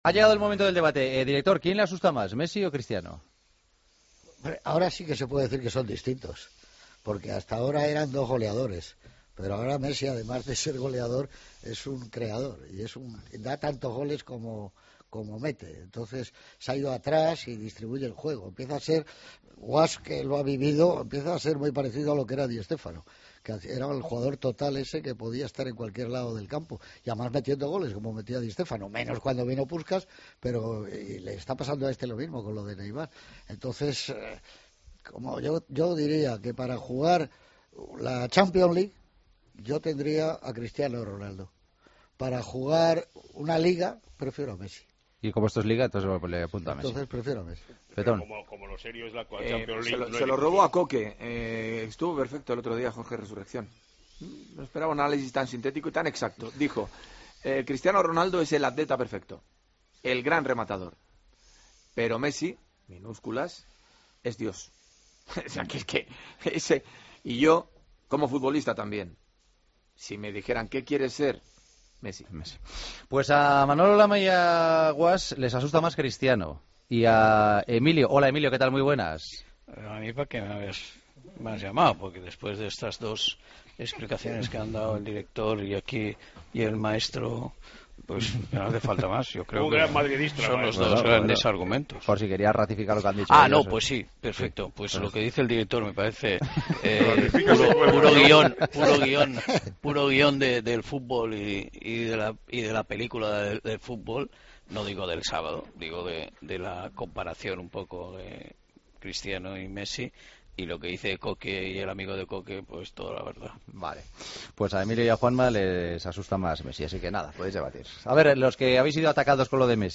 El debate de los jueves: ¿Quién da más miedo: Cristiano Ronaldo o Leo Messi?